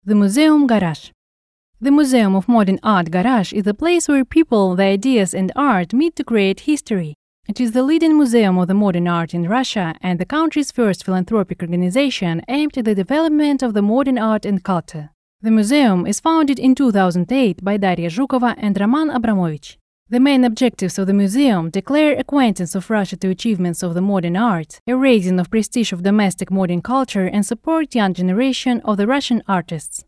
Любая подача текста на ваш выбор: мягкая и ласковая, энергичная, веселая или спокойная и загадочная.
Домашняя профессиональная студия.